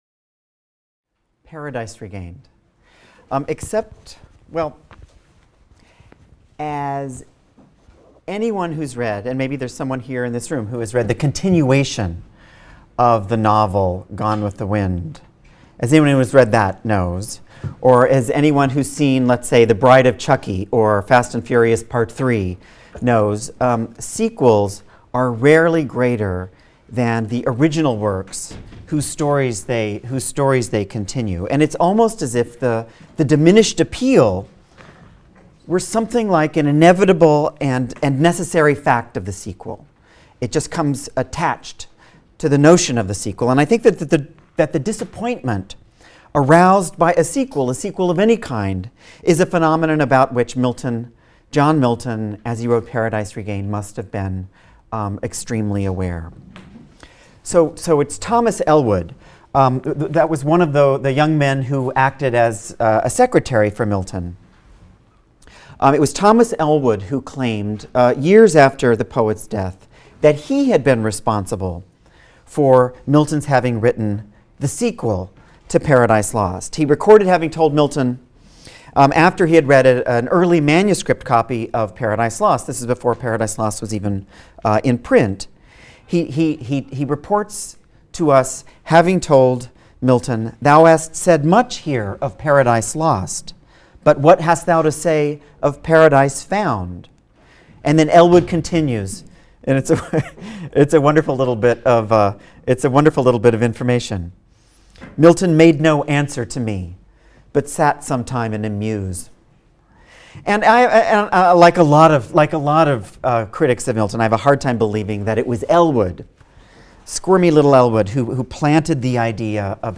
ENGL 220 - Lecture 21 - Paradise Regained, Books I-II | Open Yale Courses